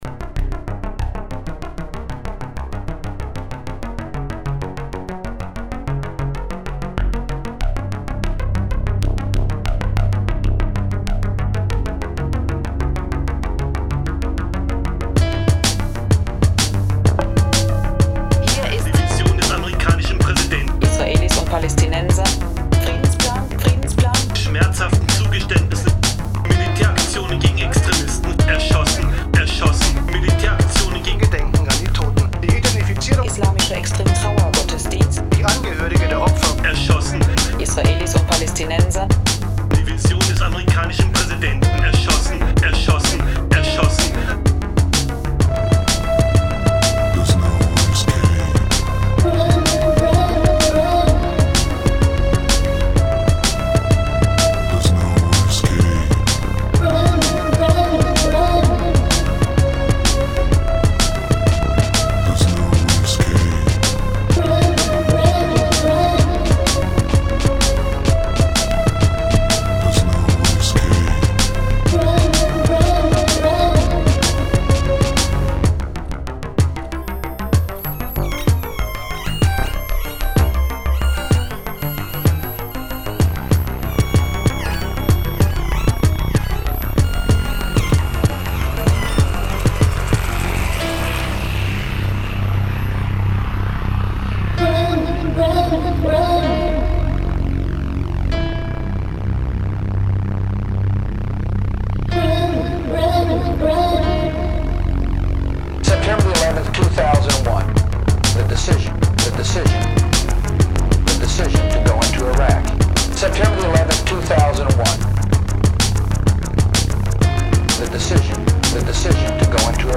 All Instruments, Vocals & Programming: